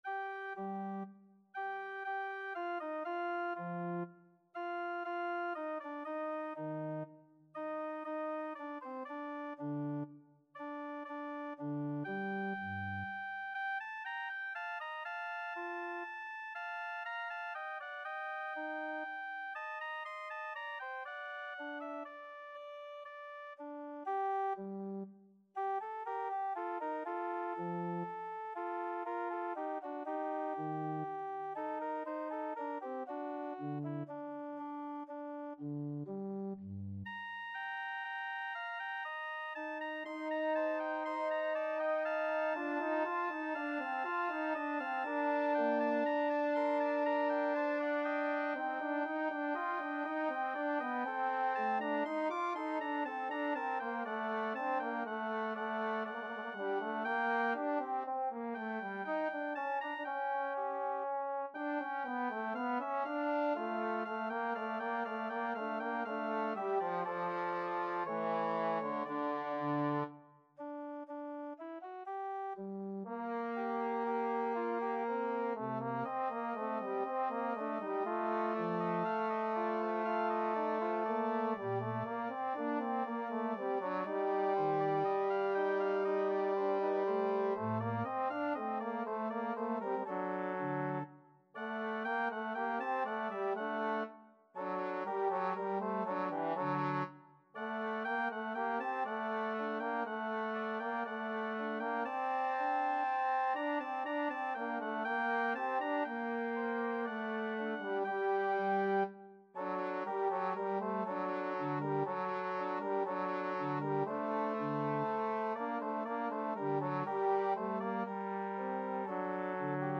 Classical Vivaldi, Antonio Concerto in A Minor, 2nd Movement, Adagio Trombone version
Trombone
3/4 (View more 3/4 Music)
G minor (Sounding Pitch) (View more G minor Music for Trombone )
Adagio
Classical (View more Classical Trombone Music)